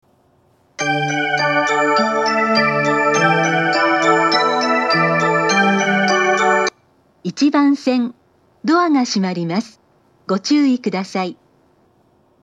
交換があると信号開通が極端に遅くなるため、メロディーは少ししか鳴りません。
２０１０年３月以前に放送装置を更新し、発車メロディーに低音ノイズが被るようになりました。
無人化の少し前に放送装置が更新され、巌根型の放送になっています。
伊東・伊豆高原・伊豆急下田方面   １番線接近放送
１番線発車メロディー メロディーは最後までなっていません。